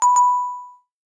cartoon
Game Show Bell Ring 2